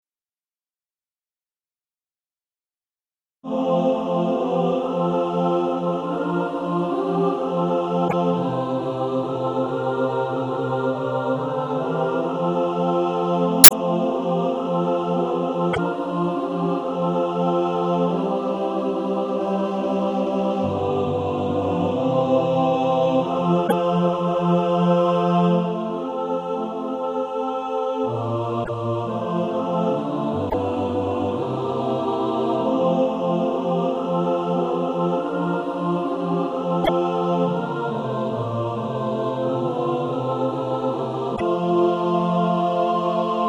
And also a mixed track to practice to
(SATB) Author
Practice then with the Chord quietly in the background.